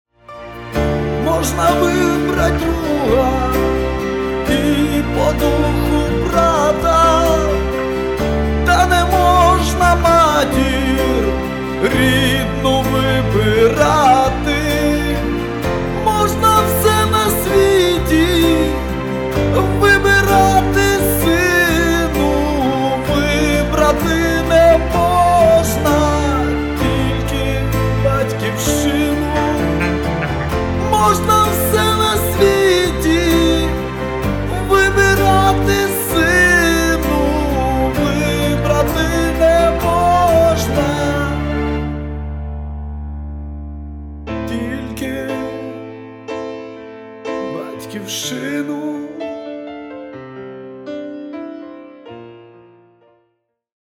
• Качество: 320, Stereo
медленные
украинские